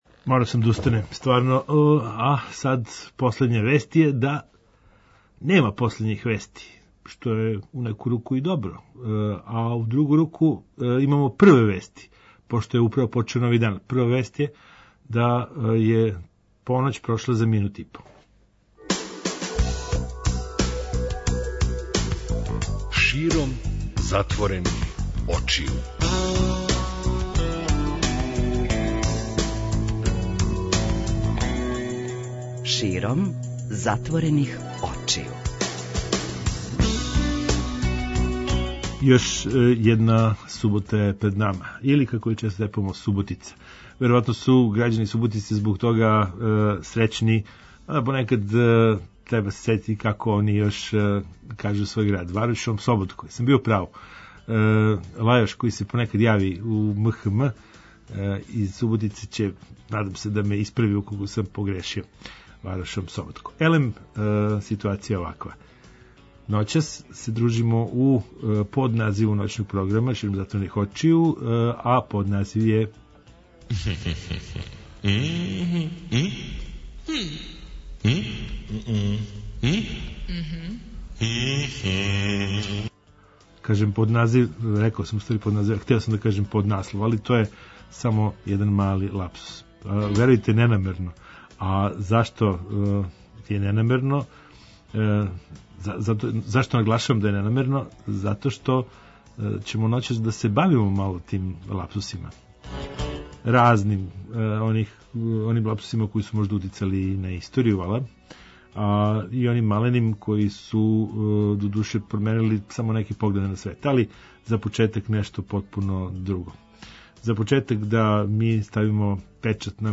преузми : 56.49 MB Широм затворених очију Autor: Београд 202 Ноћни програм Београда 202 [ детаљније ] Све епизоде серијала Београд 202 Тешке боје Пролеће, КОИКОИ и Хангар Устанак Устанак Устанак